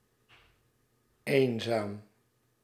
Ääntäminen
IPA: [ˈsɔ.lo]